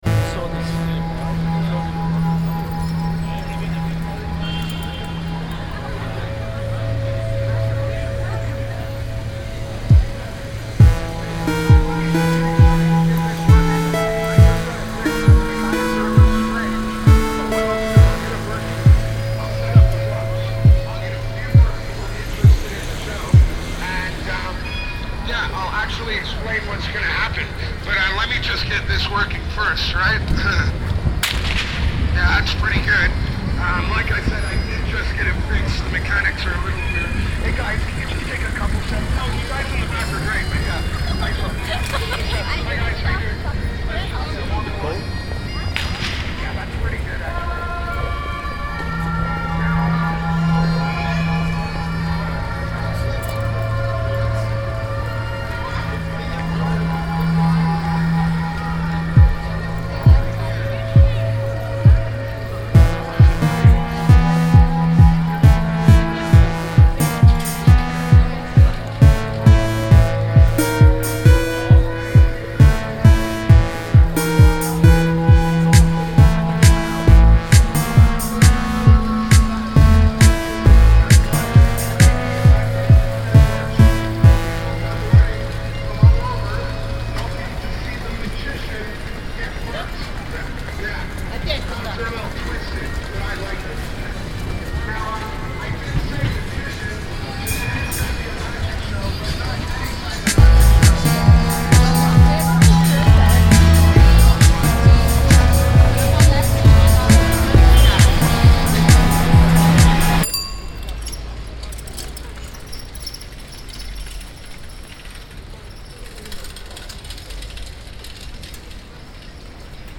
Soundscape-Stück.
Dieser unmittelbare Zugang zur Klangwirklichkeit bringt zwar nicht immer ‘reine’ und störungsfreie Aufnahmeergebnisse, dokumentiert aber vielleicht am ehesten die verschiedenen Ist-Zustände einer Stadt.
Die Stücke der Reihe Ballungen sind aufgenommen und abgemischt in der sogenannten Originalkopf-Stereophonie (binaurale Tonaufnahme) und daher angelegt für ein Hören über Kopfhörer.
Die Geräuschaufnahmen zu Ballungen I (Amsterdam) entstanden bei einem ‘Hör-Gang’ durch die Stadt Anfang April 2011.
Ausschnitt 1 setzt ein bei Minute 5’46” des Stückes. Zu hören sind Teile aus “Cipollas Bauchladen (Dam Platz II)” und “Frühling am Wasser (Brücke Prinsenstraat/Prinsengracht)” :